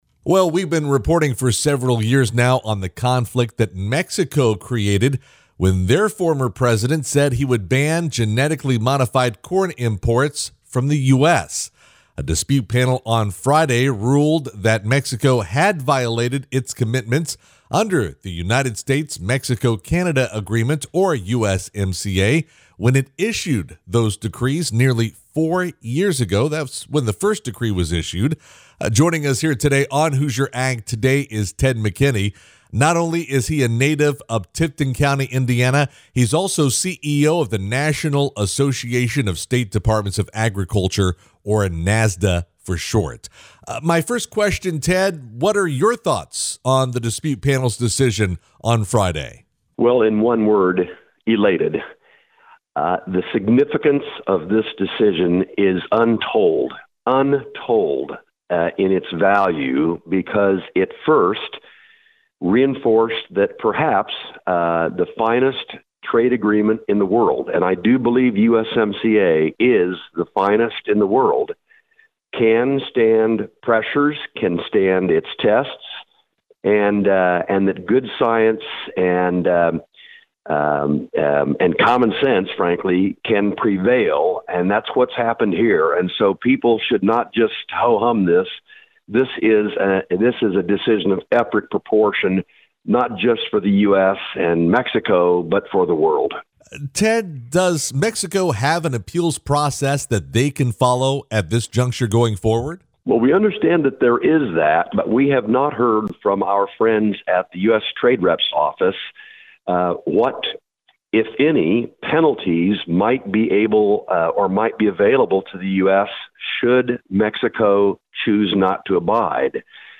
cj-full-interview-ted-mckinney-on-usmca-usda-nominees.mp3